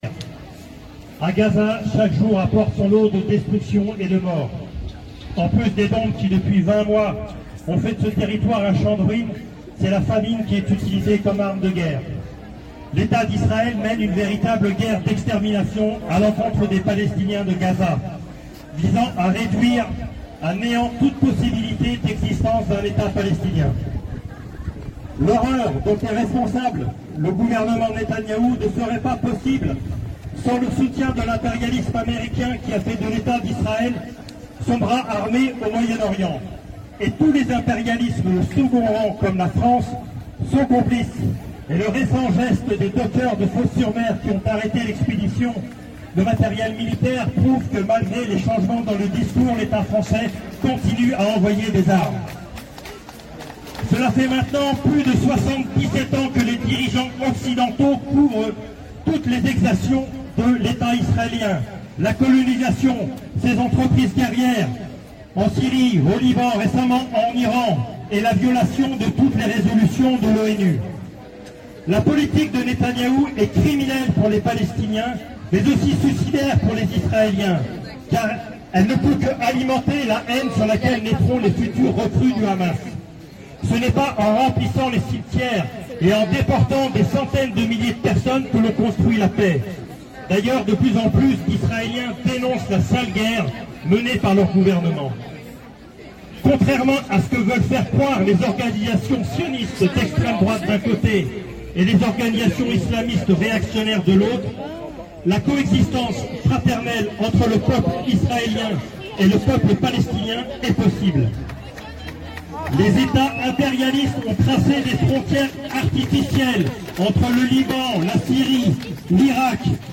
Rassemblement pour la Palestine le 13 Juin 2025 : Strasbourg